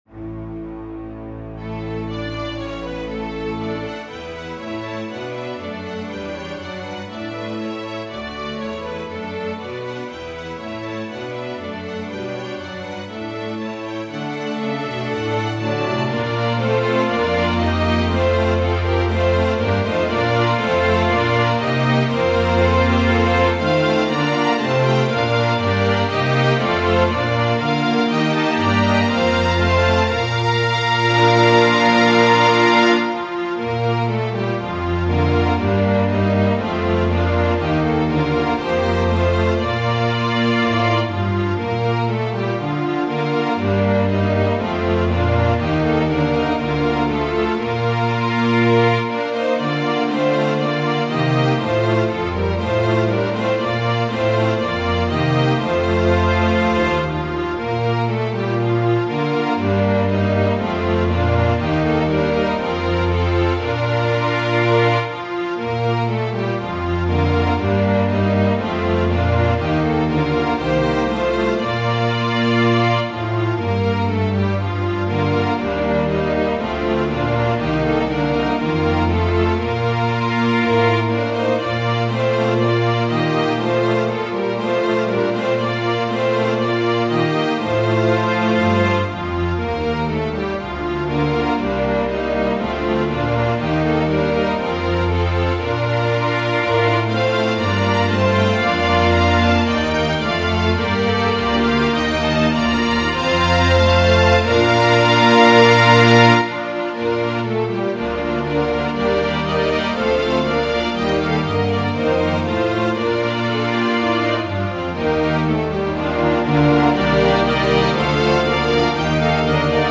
Organ/Organ Accompaniment
Of course, everything is optional to each organist but I am hoping to go for a loud opening hymn as we get closer and closer to Easter.